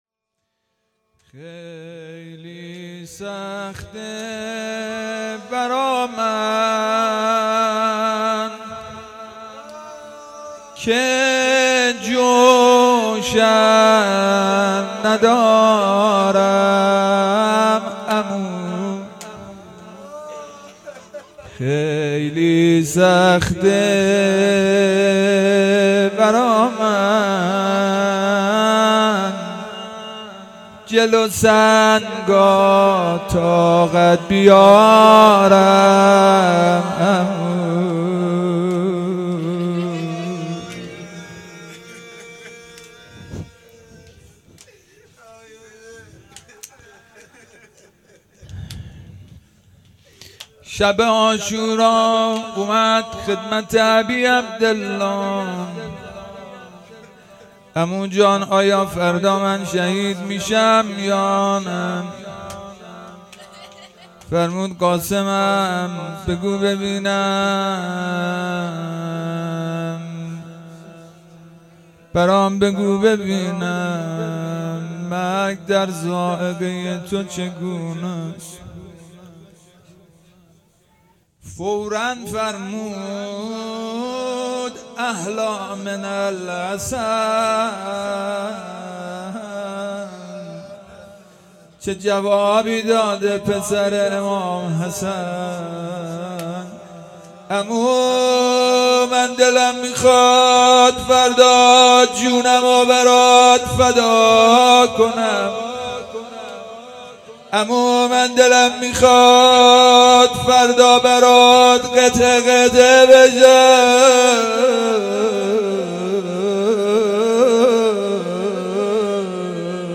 شب ششم محرم الحرام 1441